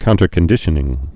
(kountər-kən-dĭshə-nĭng)